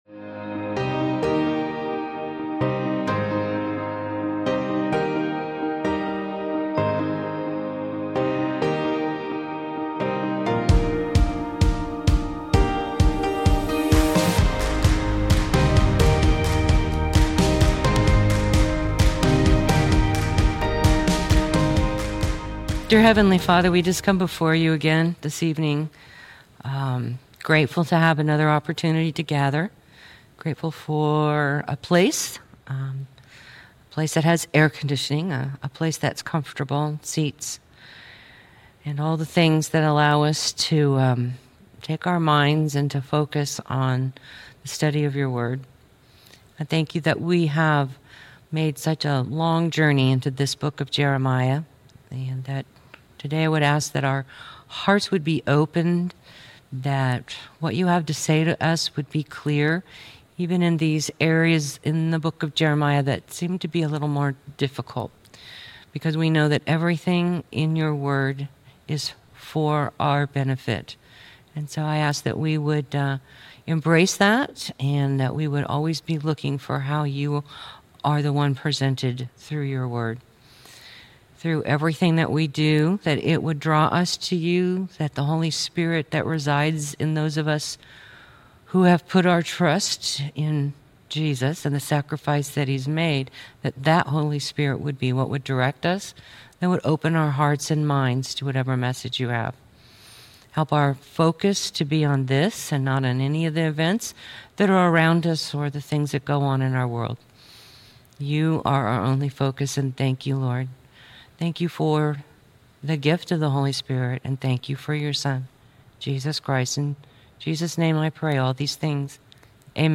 Jeremiah - Lesson 49A | Verse By Verse Ministry International